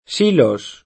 silo [ S& lo ] s. m.; pl. (raro) sili